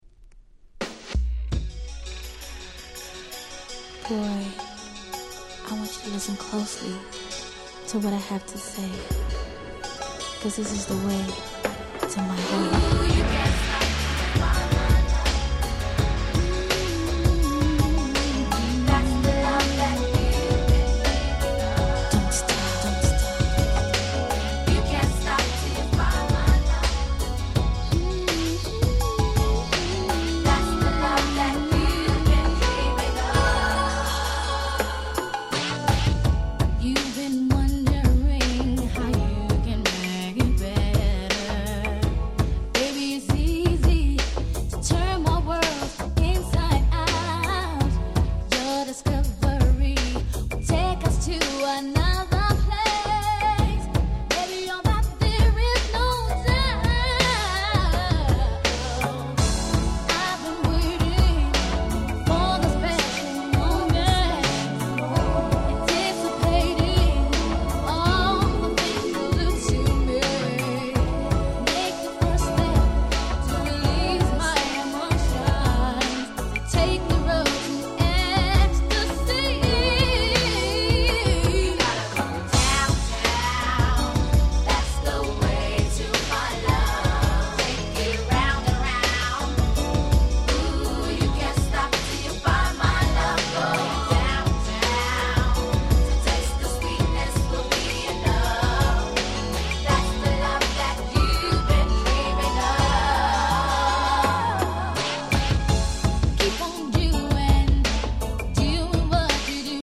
【Media】Vinyl LP
【Condition】B- (薄いスリキズ多め。プチノイズ箇所あり。試聴ファイルでご確認願います。)
92' Big Hit R&B LP !!